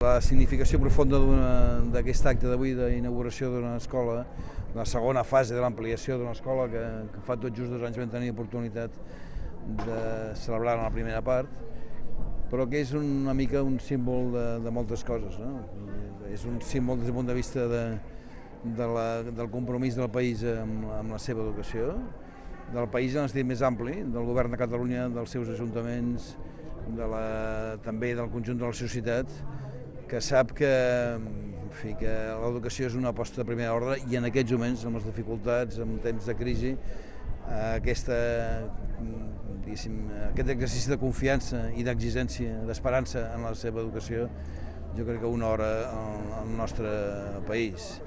Tall de veu conseller inauguració escola